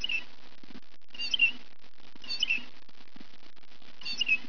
Listen for a "queedle, queedle" musical kind of song, with the "quee" being higher than the "dle" part. It can also belt out a raucous "jay-jay" call.
Blue Jay WAV file
bluejay_2.wav